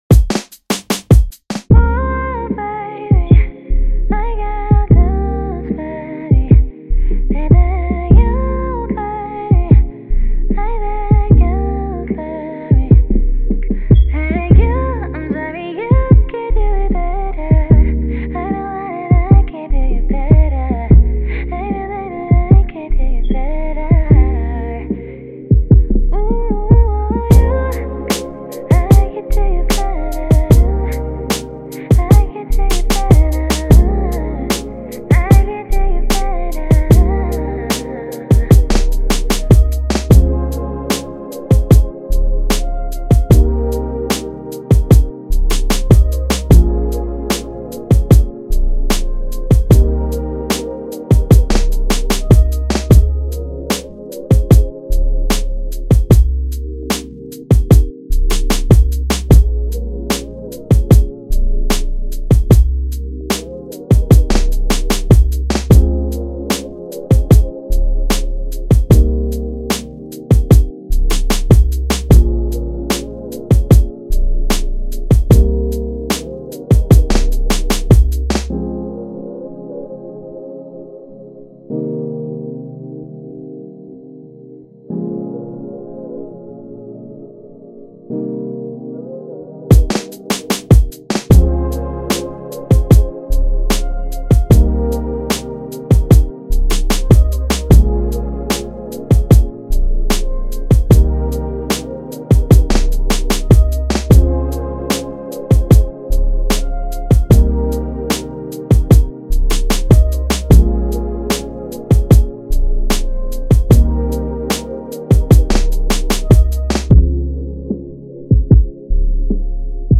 R&B
F Minor